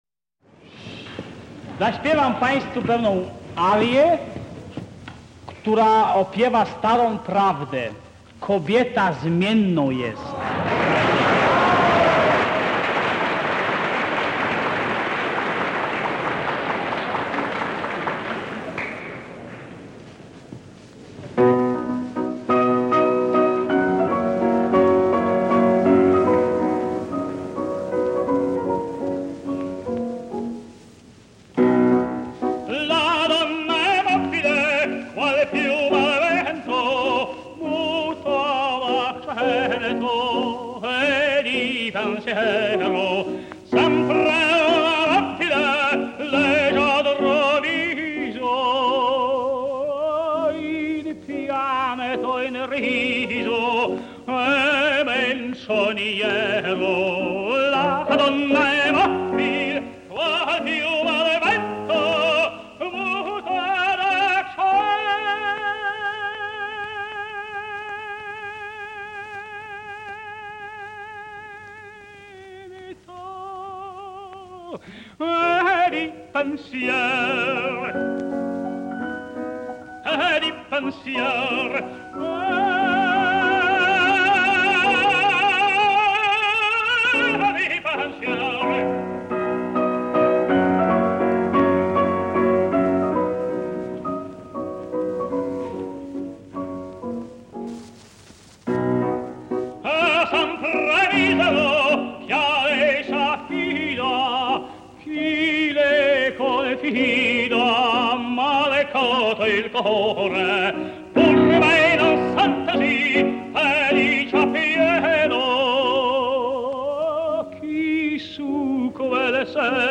słynny tenor